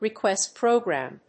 アクセントrequést prògram